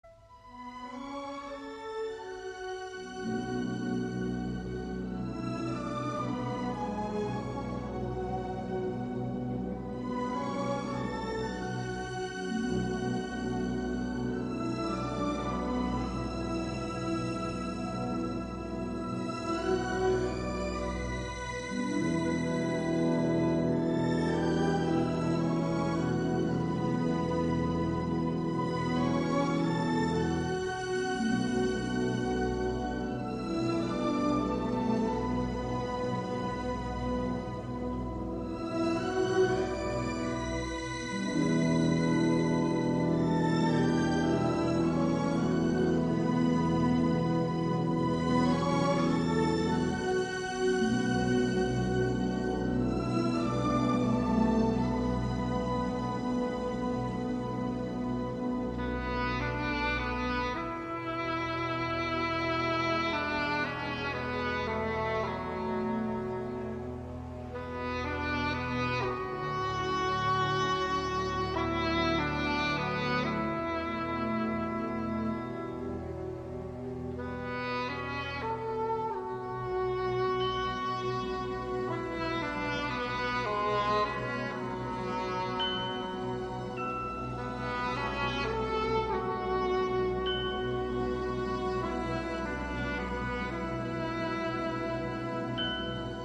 bucólica partitura de raíces folklóricas